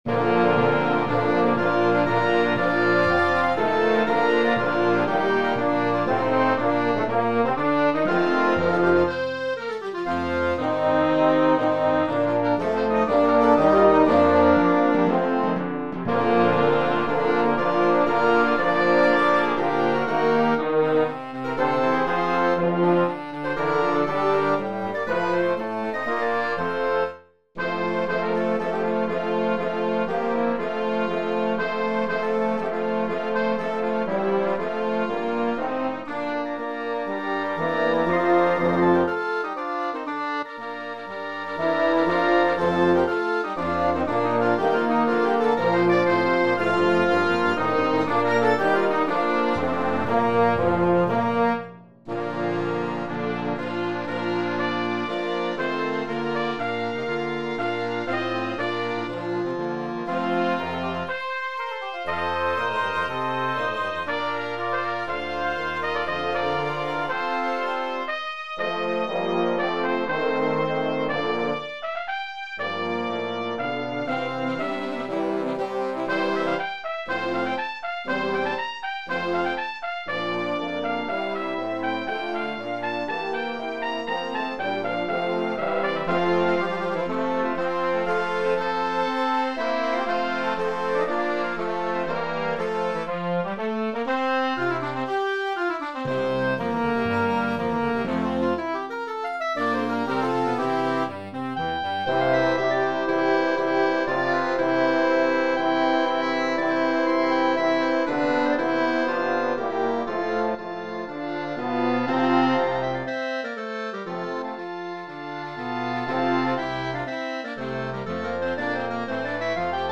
Voicing: Trumpet and Concert Band